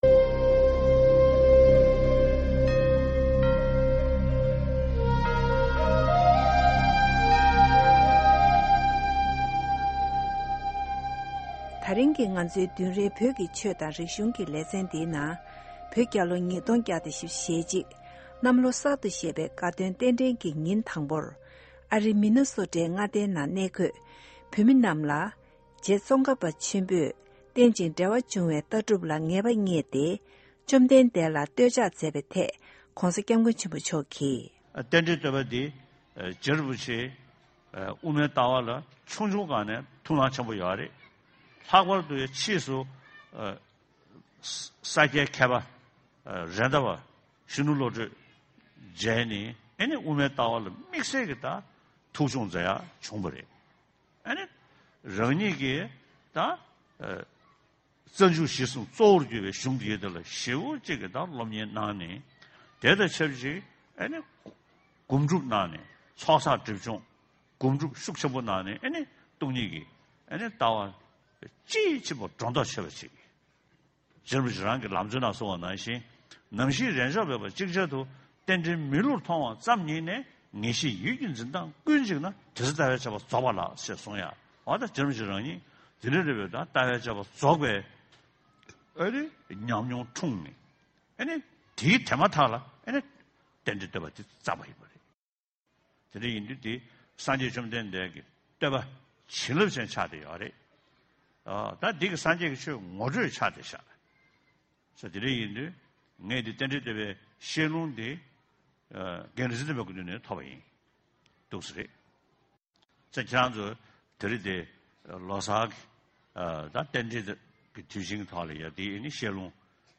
His Holiness gave teachings on 'Tendrel Toepa,' Je Tsongkhapa's praise to the Buddha for his teachings on 'Dependent Origination'. His Holiness says that the concept of dependent origination can be used in the fields of modern day economy, business, education, and environment.